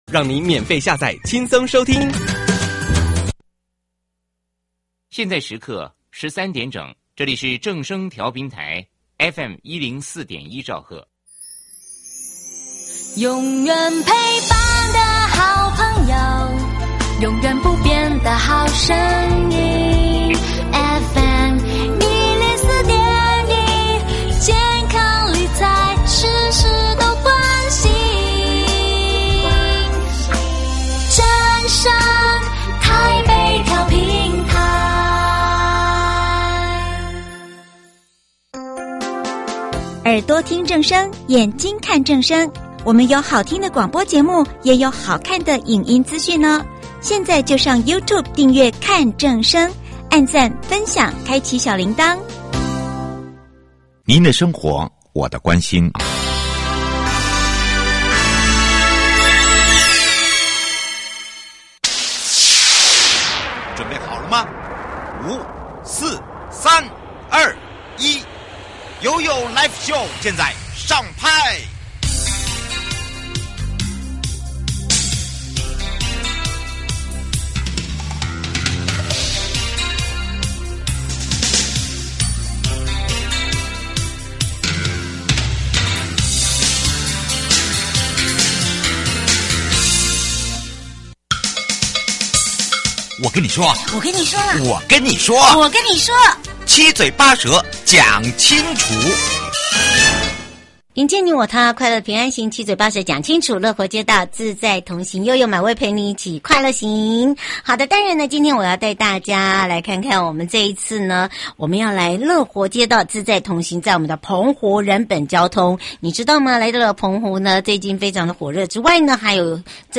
受訪者： 1.國土署都市基礎工程組